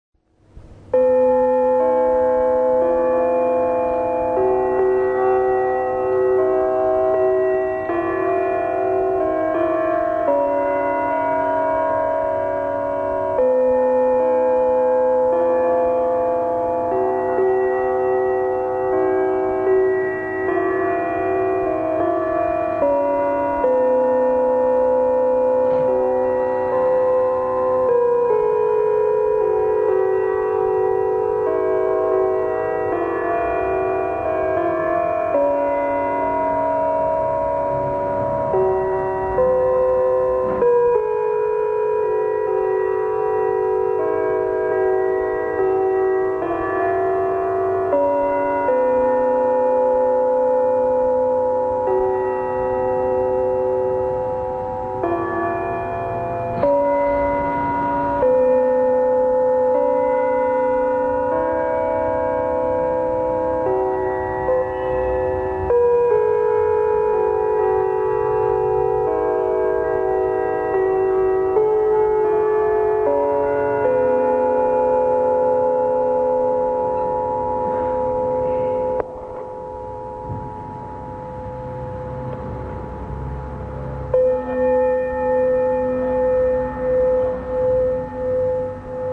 市役所のチャイム
豊岡市役所の屋根に設置されている４方向に向いたスピーカーから午後５時と午後９時に鳴らされるチャイムです、現在は少し音色が違うようです。
chime.mp3